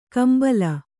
♪ kambala